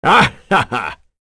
Phillop-Vox_Happy2.wav